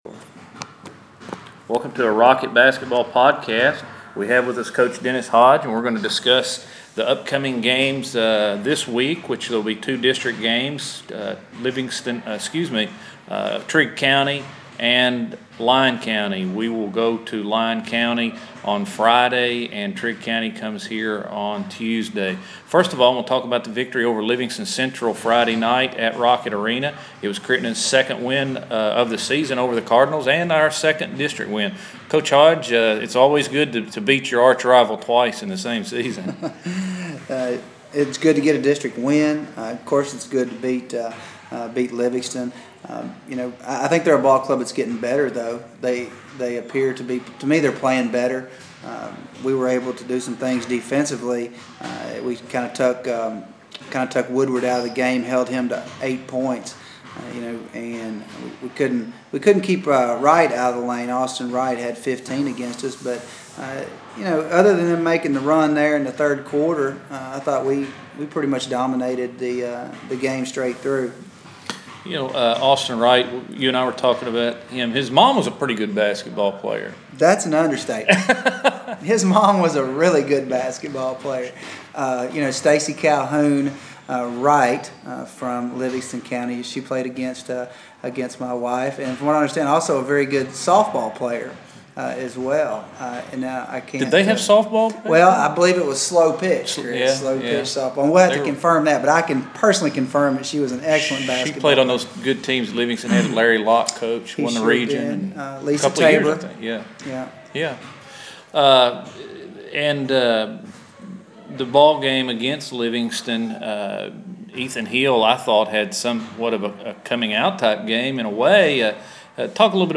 INTERVIEW
Conducted after the boys' second win over Livingston Central